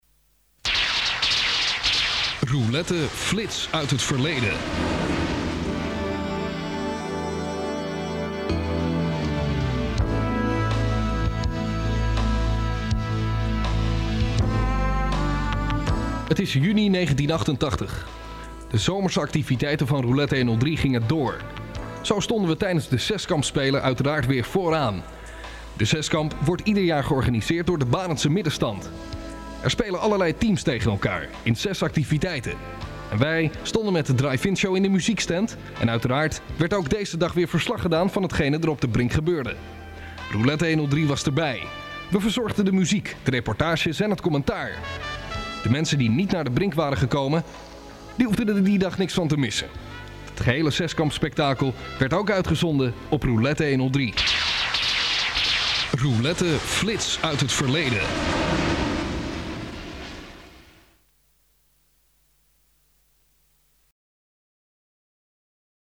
Alles op cassette en spoelen (bandrecorders)